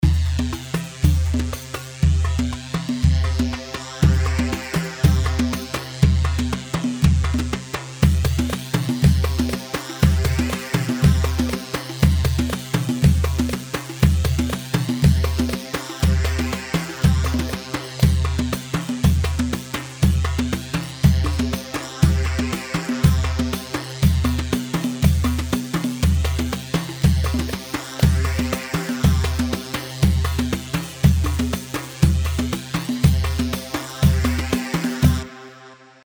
Hewa 4/4 120 هيوا
Hewa-120.mp3